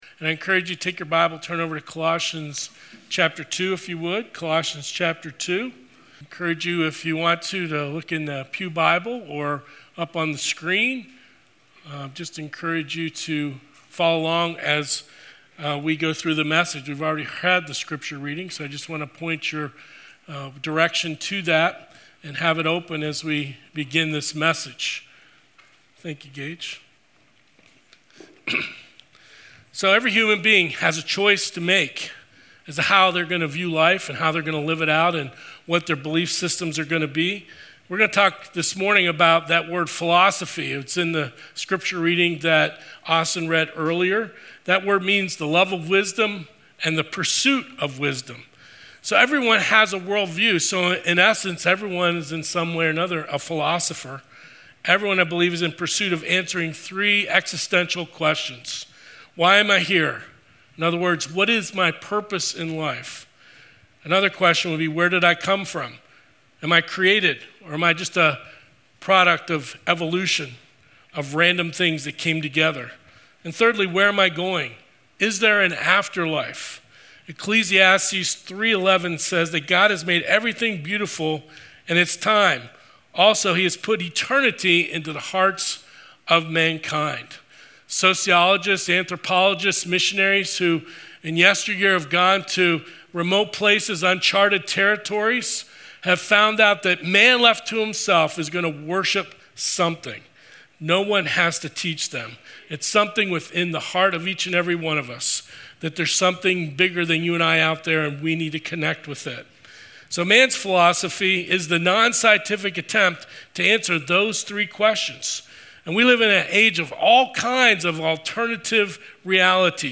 Sermons | Pleasant View Baptist Church